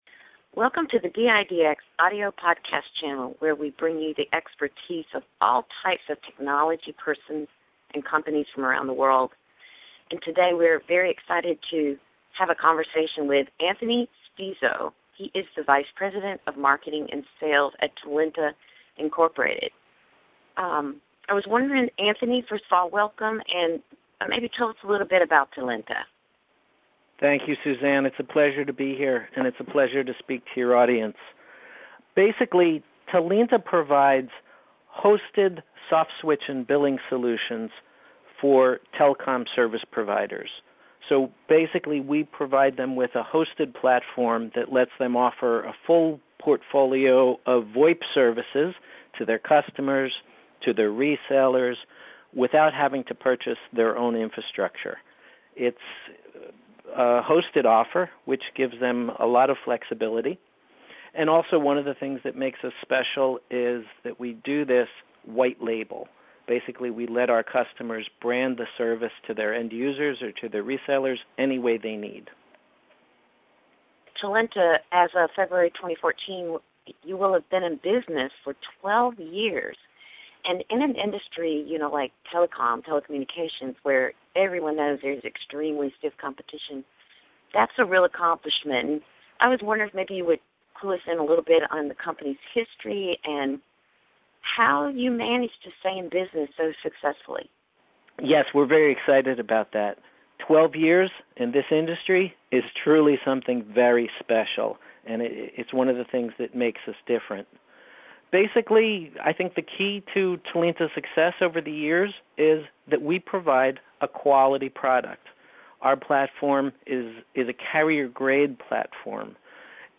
Interview with DIDx